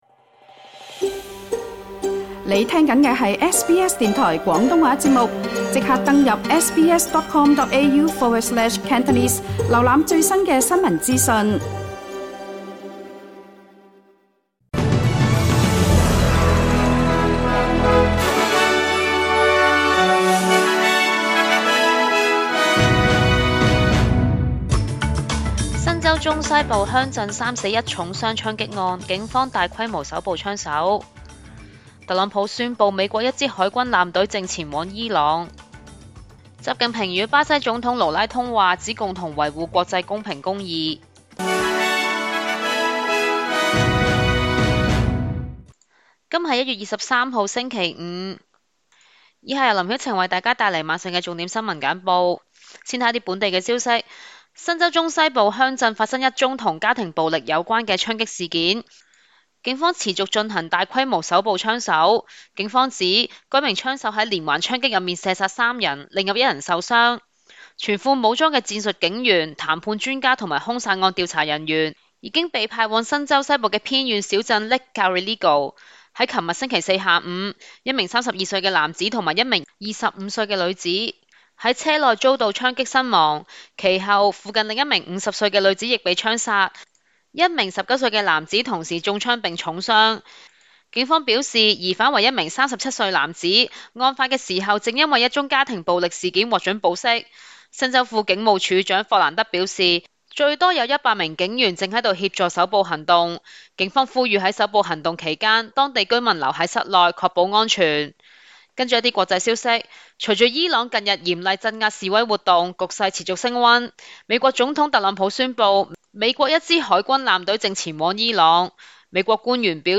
請收聽本台為大家準備的每日重點新聞簡報。